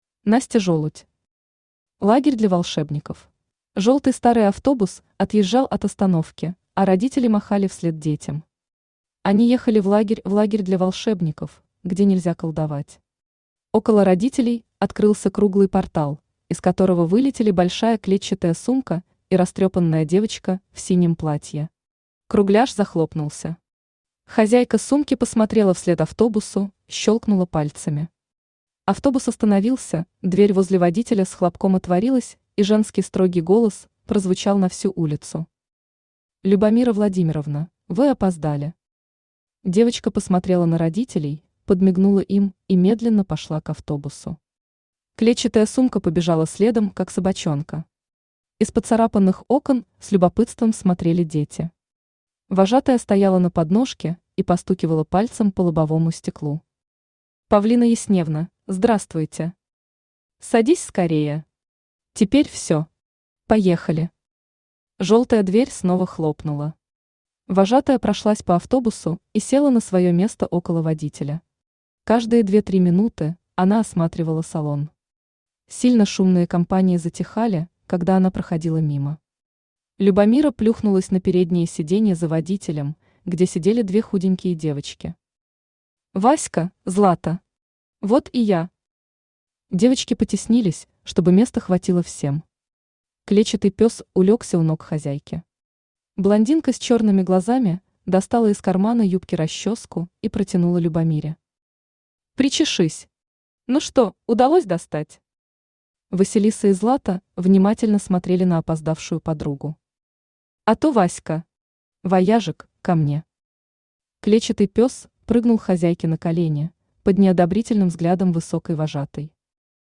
Аудиокнига Лагерь для волшебников | Библиотека аудиокниг
Aудиокнига Лагерь для волшебников Автор Настя Жолудь Читает аудиокнигу Авточтец ЛитРес.